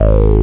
303-growl2.mp3